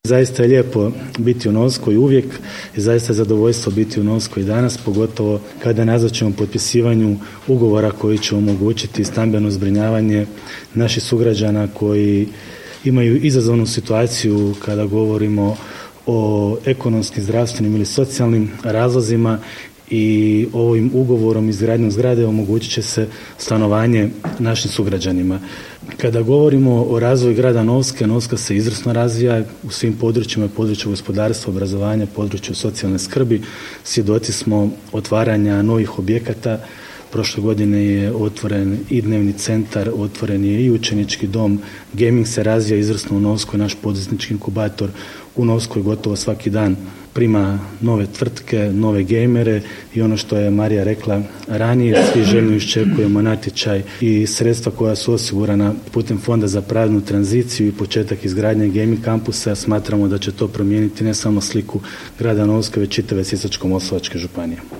Sisačko-moslavački župan Ivan Celjak